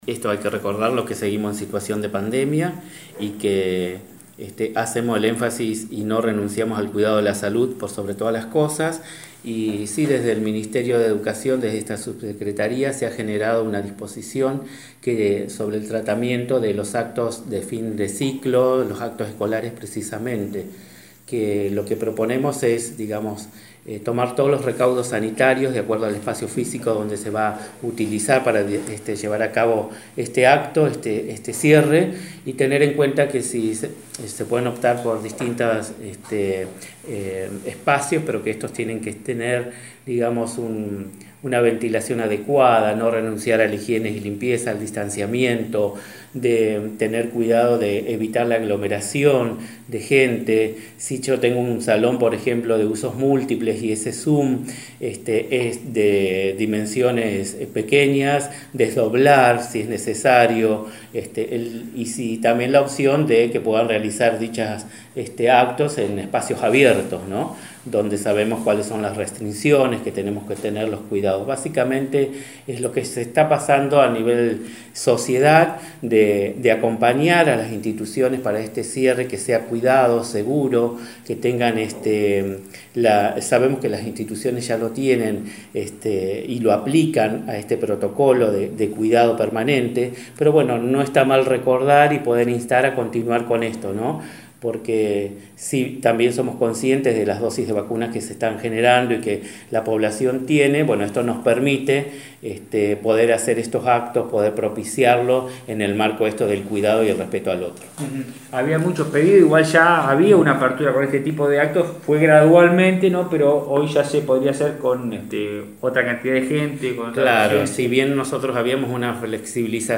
En diálogo con Bahía Engaño, el subsecretario de Educación de la Provincia, Miguel Casanova, indicó que “proponemos tomar todos los recaudos sanitarios de acuerdo al espacio físico que se utilizará para llevar a cabo este cierre. Teniendo en cuenta que se pueden optar por distintos espacios pero que deben tener una ventilación adecuada, no renunciar a la higiene, el distanciamiento, evitar la aglomeración de gente”.